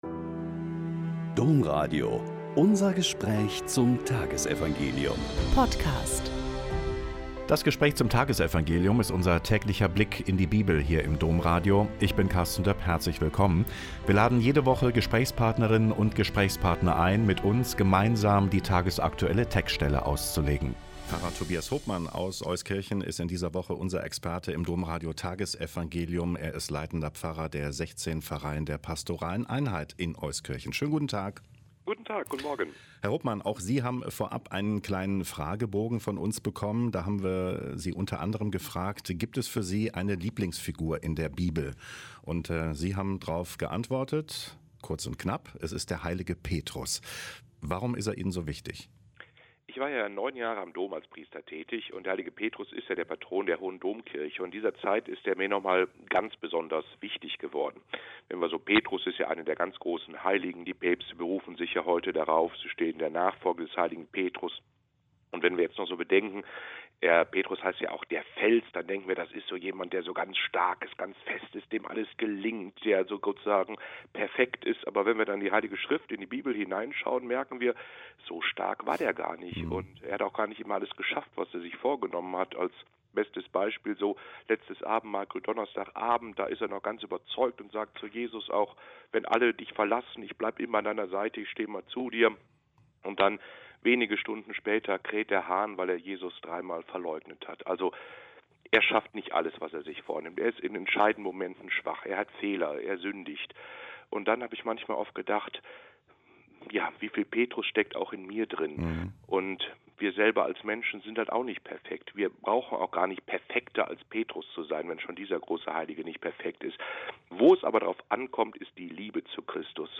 Lk 9,22-25 - Gespräch